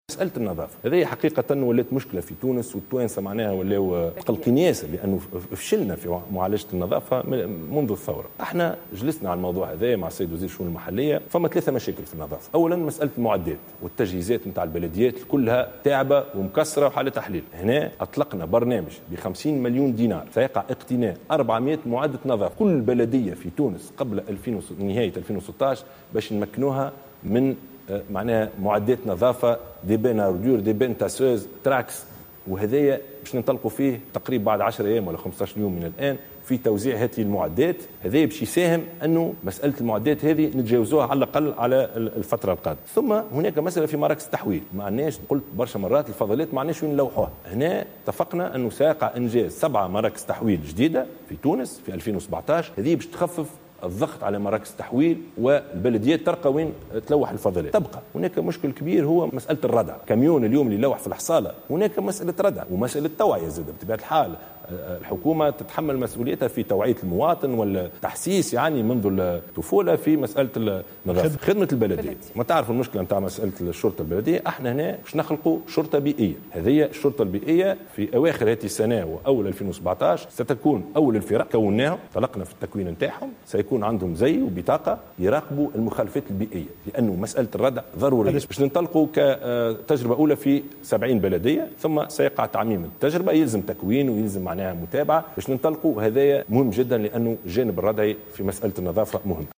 أعلن رئيس الحكومة يوسف الشاهد في حوار مع القناة الوطنية الأولى مساء أمس الأربعاء 28 سبتمبر 2016 أن الحكومة أعدت برنامجا كاملا في مجال النظافة والعناية بالبيئة بقيمة 50 مليون دينار.